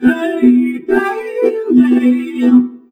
VOXVOCODE3-L.wav